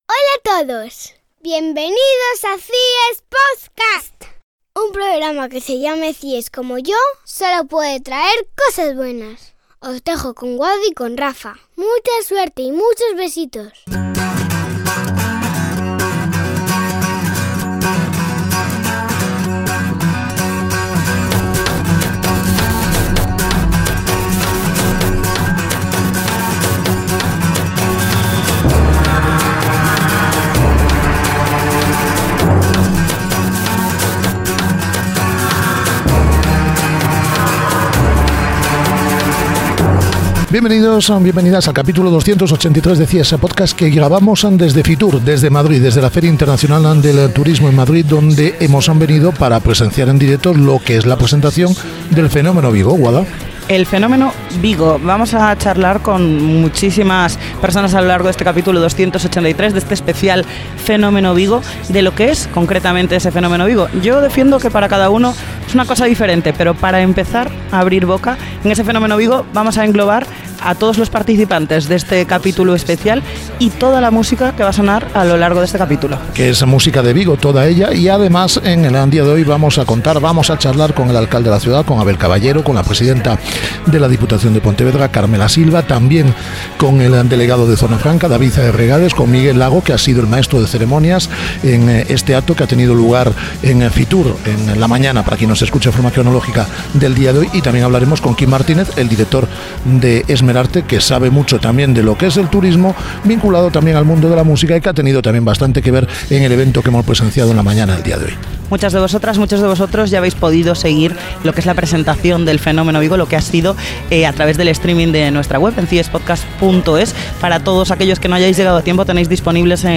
Capítulo especial, desde FITUR, en el cual analizamos ‘El fenómeno Vigo’ con Abel Caballero